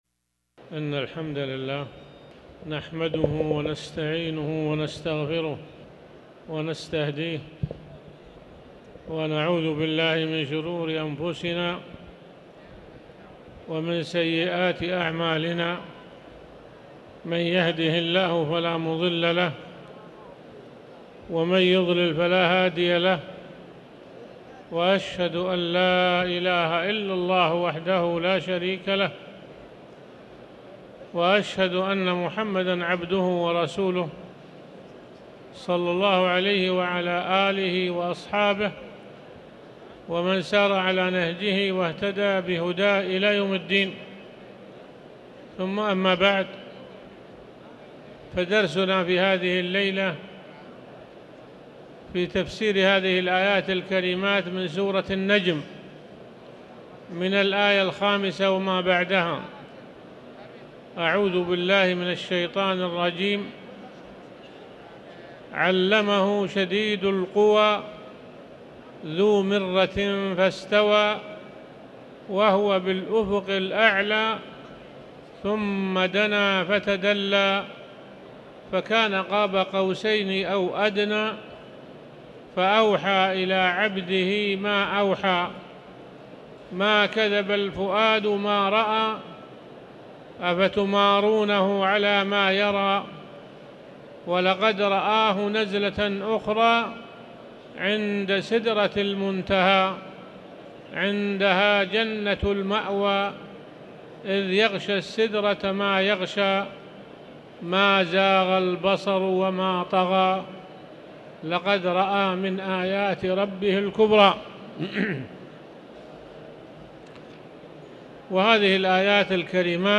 تاريخ النشر ٨ جمادى الأولى ١٤٤٠ هـ المكان: المسجد الحرام الشيخ